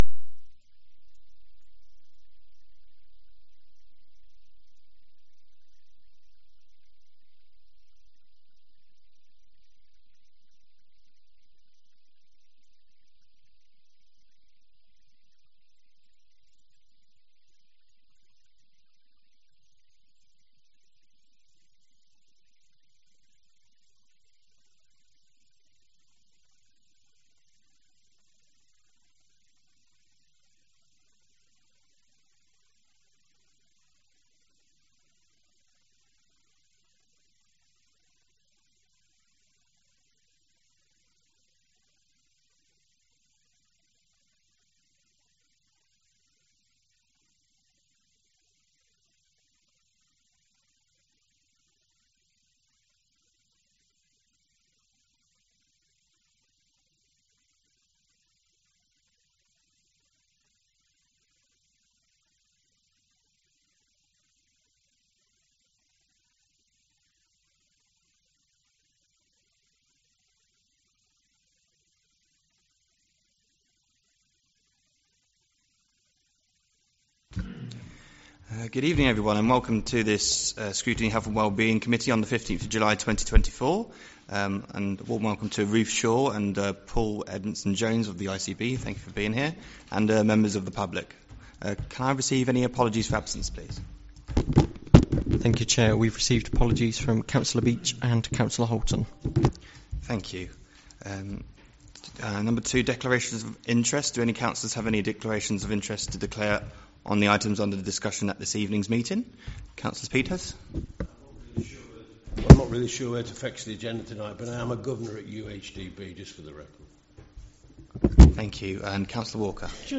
Committee Scrutiny Health and Well Being Committee Meeting Date 15-07-24 Start Time 6.30pm End Time 7.18pm Meeting Venue Coltman VC Room, Town Hall, Burton upon Trent Please be aware that not all Council meetings are live streamed.
Meeting Recording 240715.mp3 ( MP3 , 15.25MB )